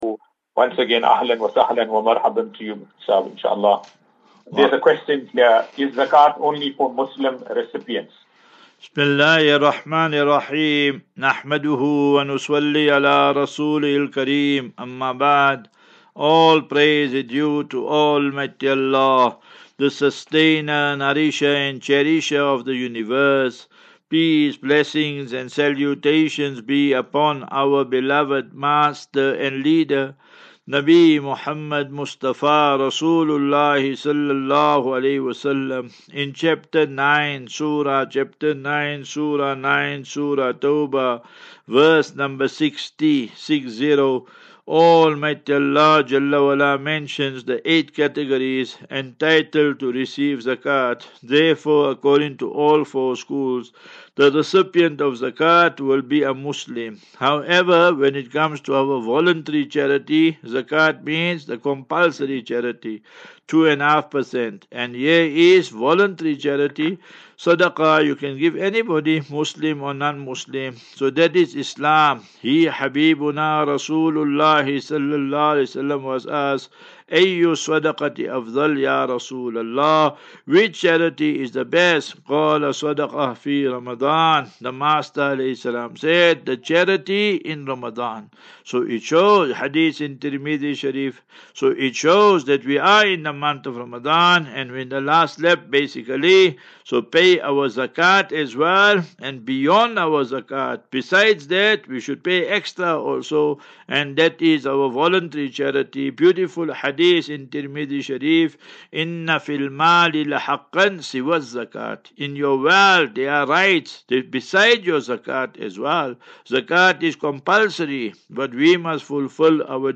View Promo Continue Install As Safinatu Ilal Jannah Naseeha and Q and A 3 Apr 03 April 2024.